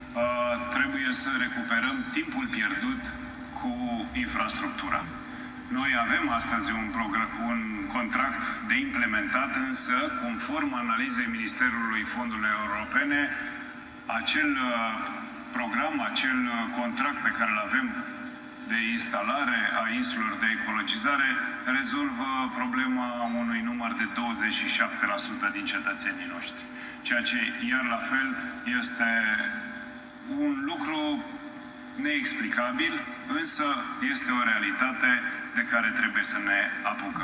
Dar, chiar și odată cu implementarea programului de creare a infrastructurii de colectare selectivă existent, insulele ecologice de colectare a deșeurilor nu vor rezolva problema decât pentru 27% dintre cetățenii Sectorului, le-a spus George Tuță consilierilor locali din Banu Manta.